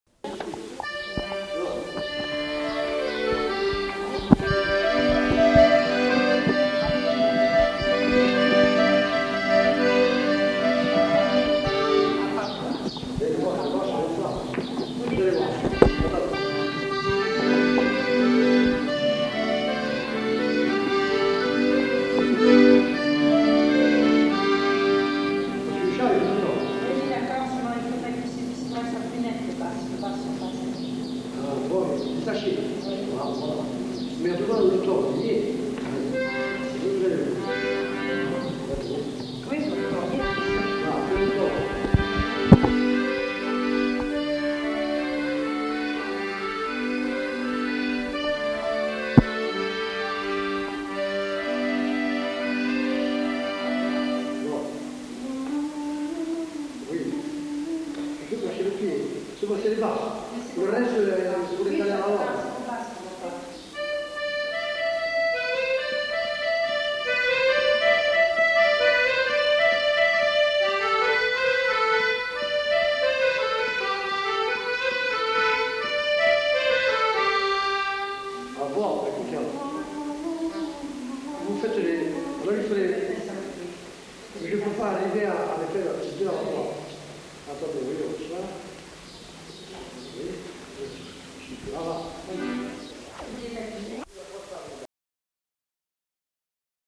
Aire culturelle : Couserans
Genre : morceau instrumental
Instrument de musique : accordéon chromatique
Danse : bourrée